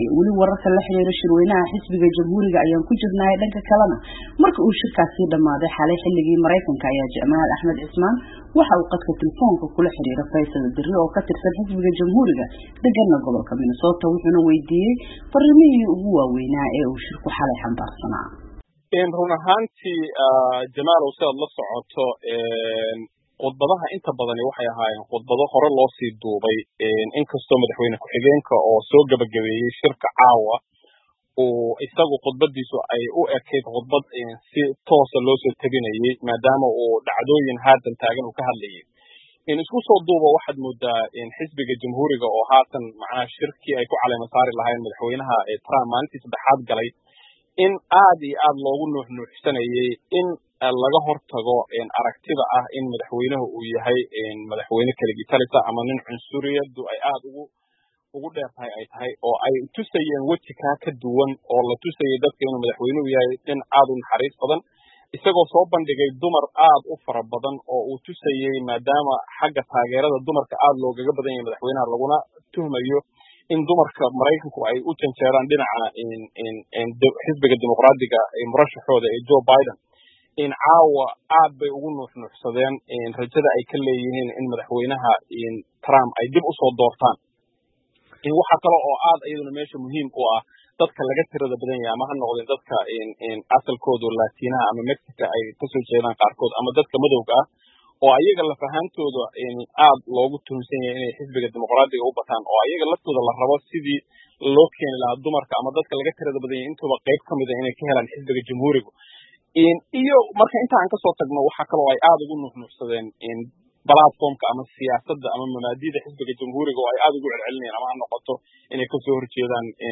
Falanqeyn: Shirweynaha Jamhuuriga